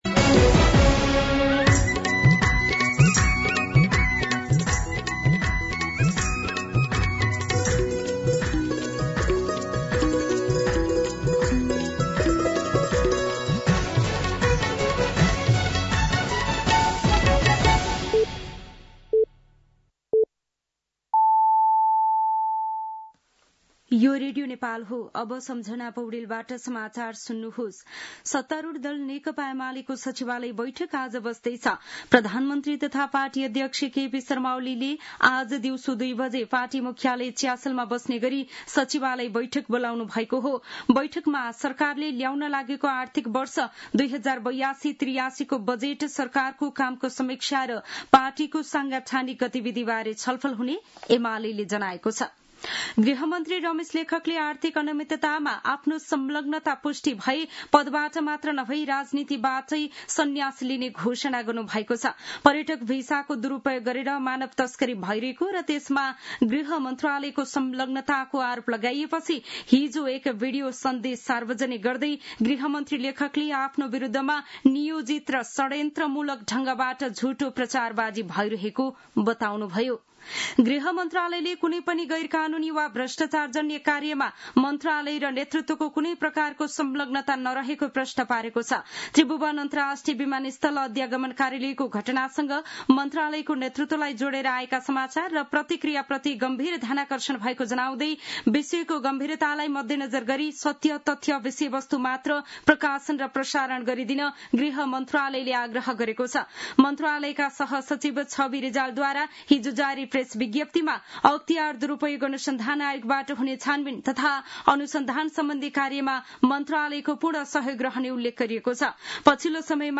मध्यान्ह १२ बजेको नेपाली समाचार : ११ जेठ , २०८२